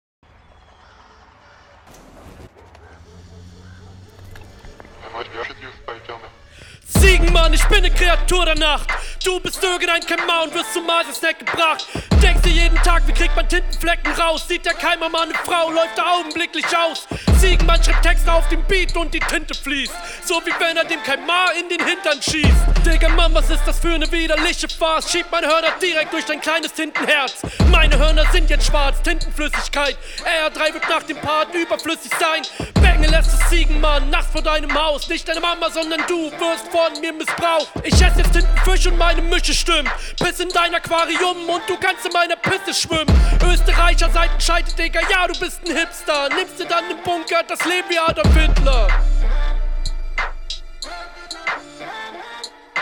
Stimme knallt rein, deutlich besser.